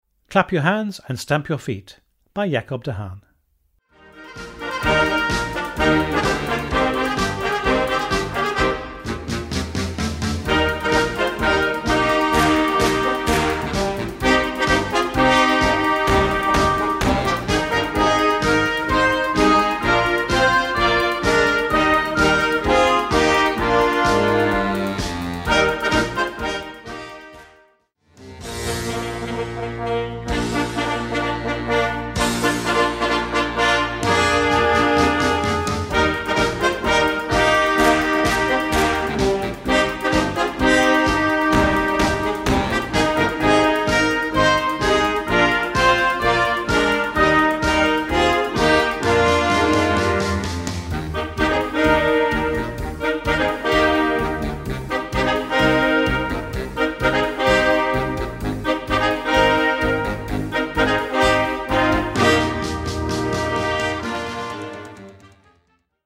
Category: FLEXI - BAND